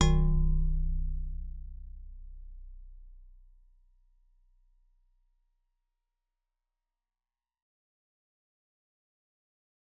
G_Musicbox-B0-f.wav